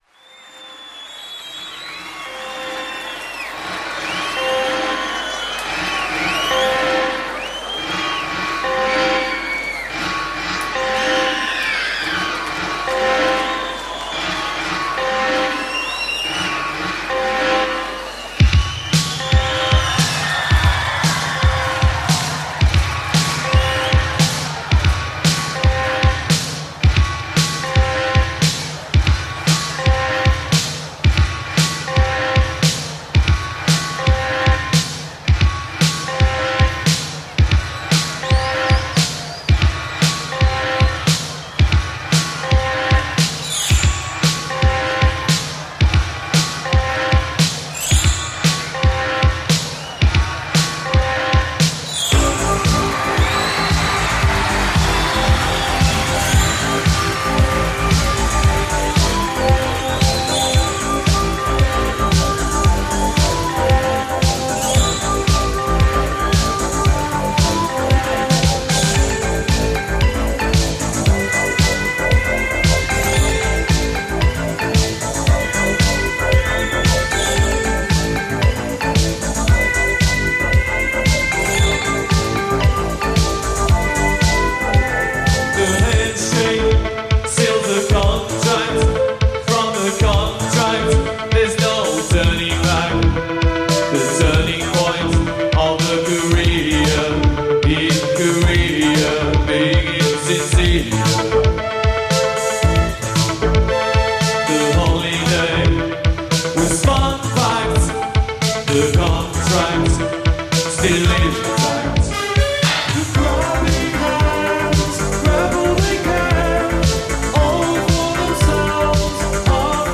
heavily electronic sound
Because their music was dark in tone and moody in execution.